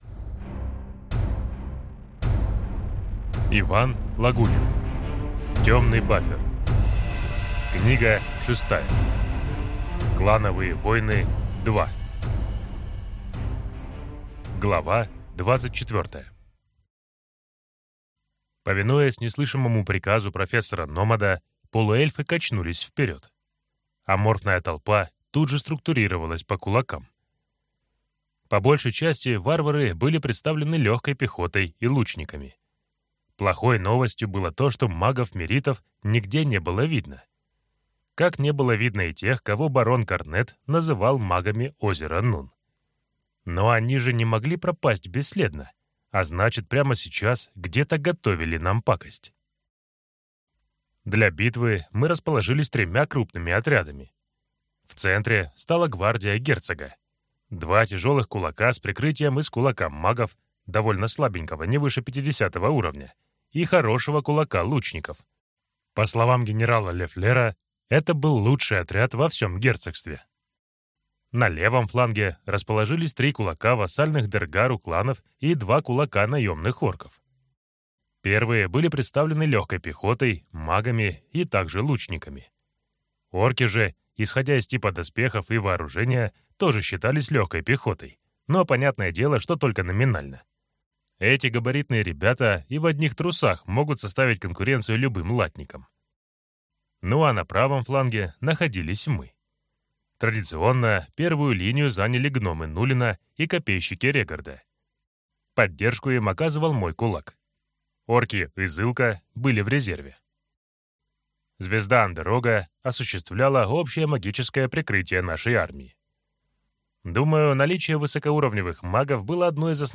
Аудиокнига Темный баффер. Книга 6. Клановые войны 2 | Библиотека аудиокниг